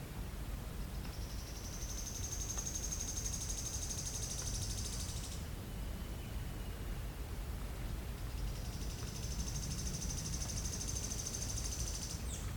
Scientific name: Lochmias nematura nematura
English Name: Sharp-tailed Streamcreeper
Location or protected area: Serra da Cantareira
Condition: Wild
Certainty: Observed, Recorded vocal